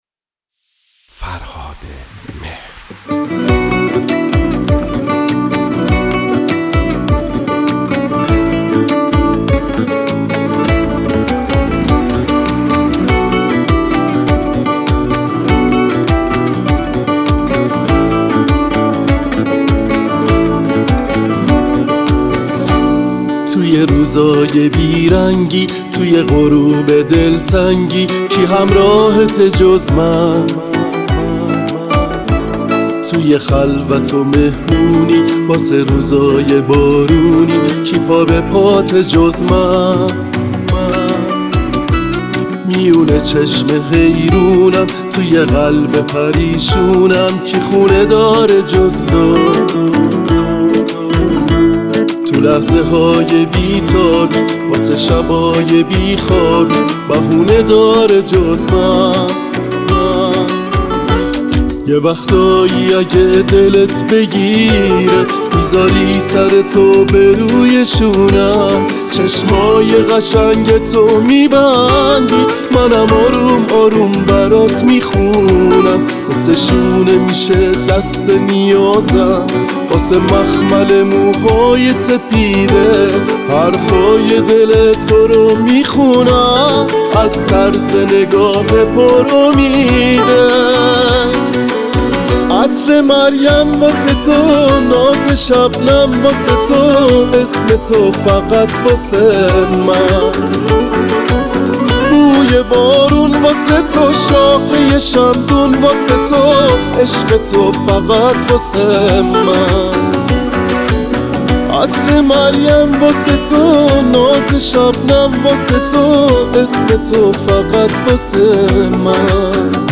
گیتار کلاسیک